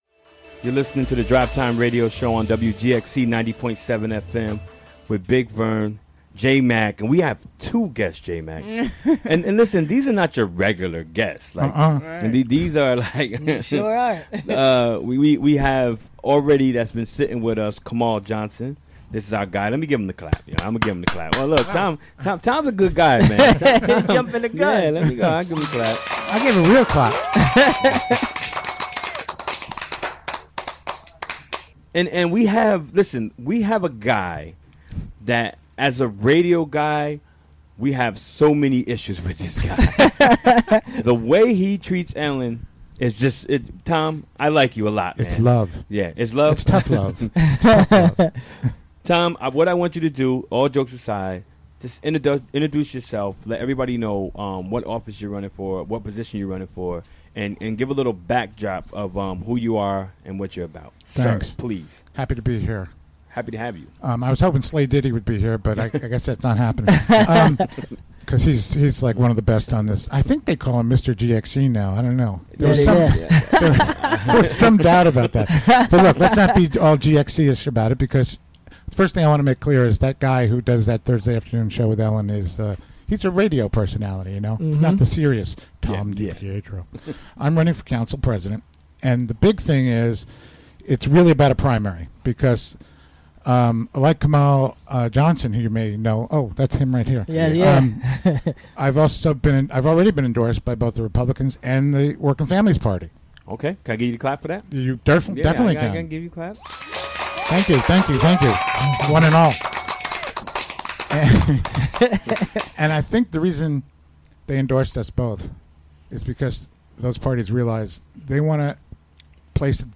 Recorded during the WGXC Afternoon Show Wednesday, August 9, 2017.